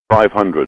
IDG-A32X/Sounds/GPWS/altitude-500.wav at 2bbc3a346bddd160c5be037bc25c04d51e5ee4f4